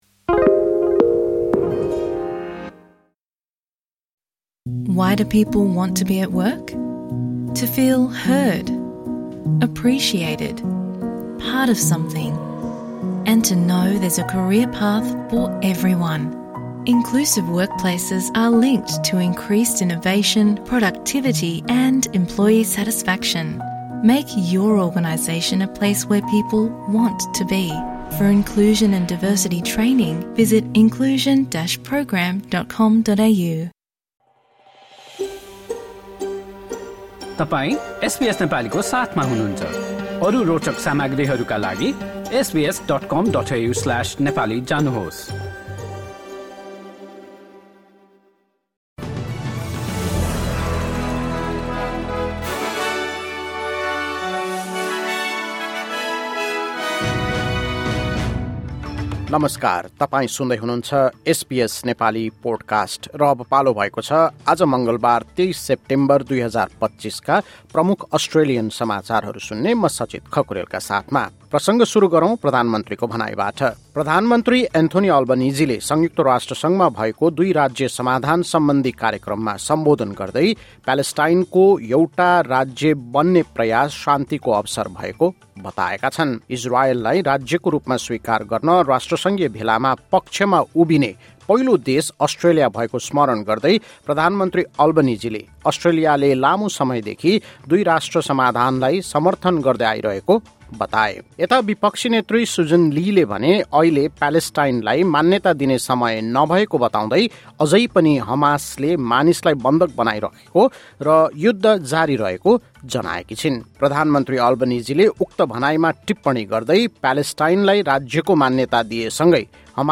SBS Nepali Australian News Headlines: Tuesday, 23 September 2025